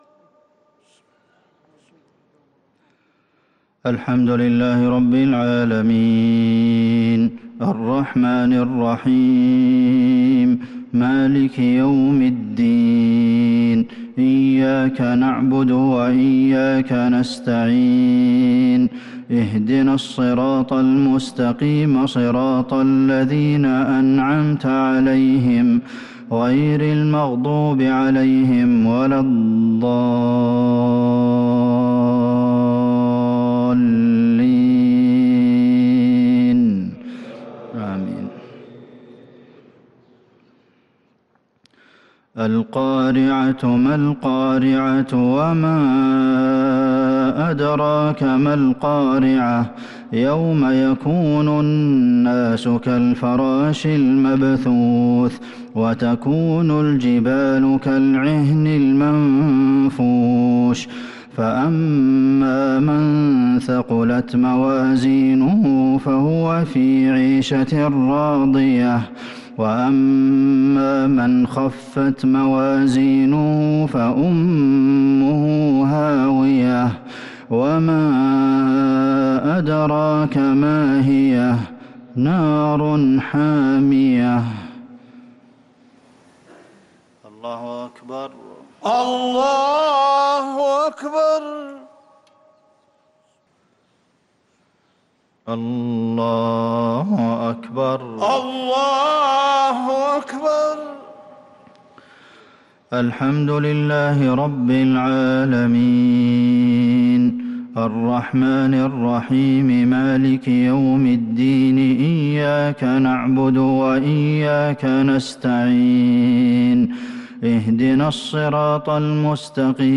مغرب الثلاثاء 5-8-1443هـ سورتي القارعة والمسد | Maghrib prayer Surah Al-Qari'ah and Al-Masad 8-3-2022 > 1443 🕌 > الفروض - تلاوات الحرمين